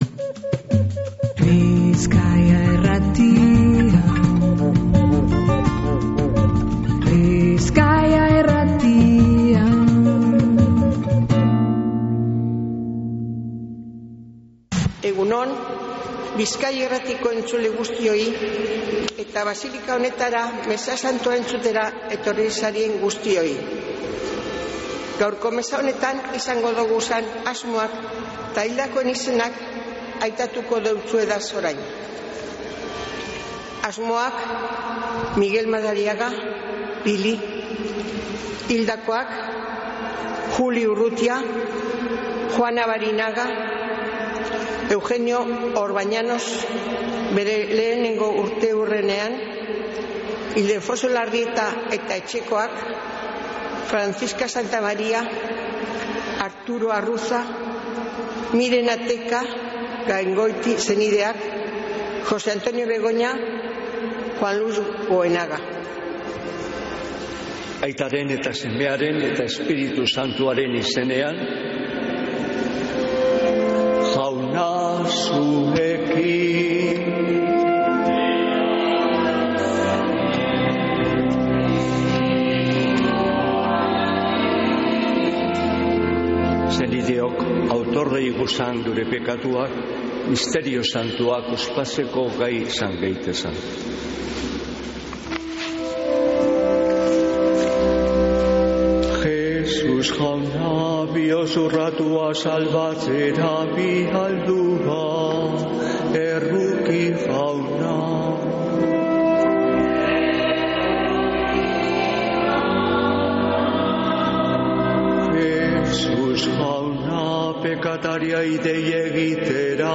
Mezea Begoñako basilikatik | Bizkaia Irratia
Mezea